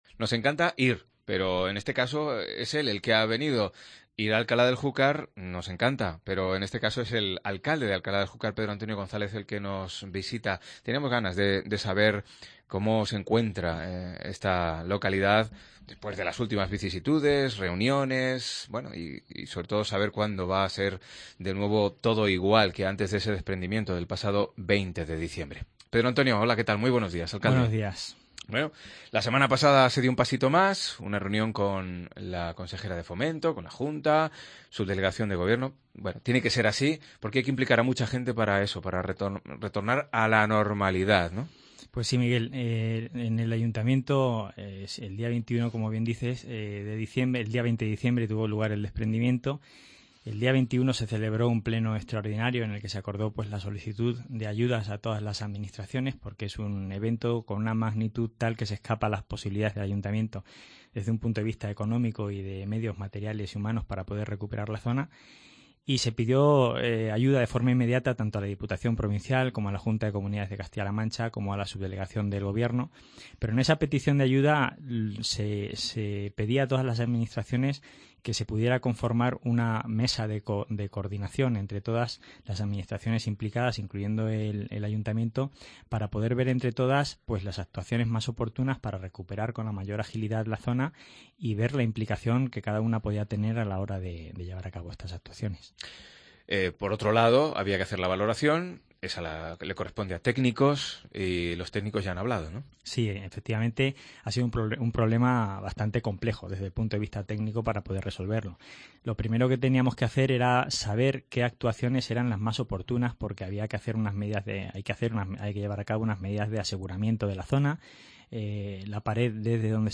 170203 Entrevista Pedro Antonio González alcalde Alcalá del Júcar